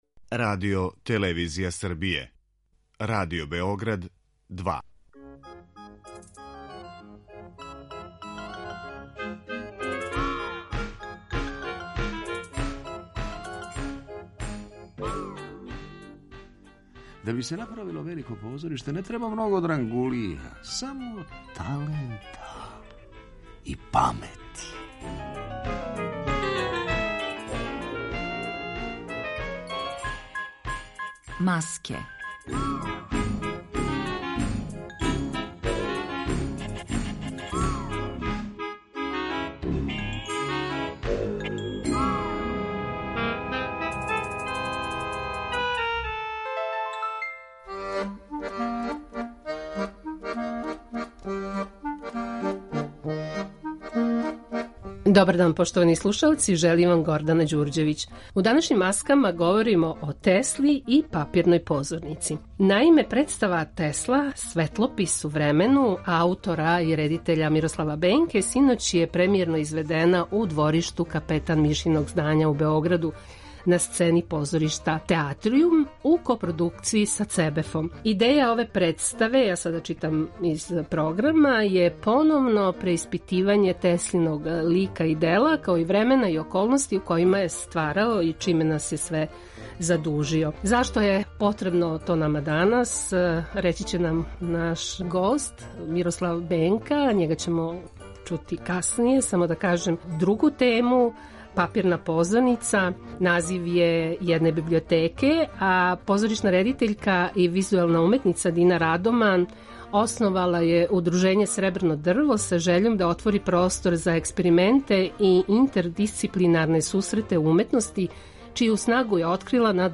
у разговору